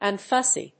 unfussy.mp3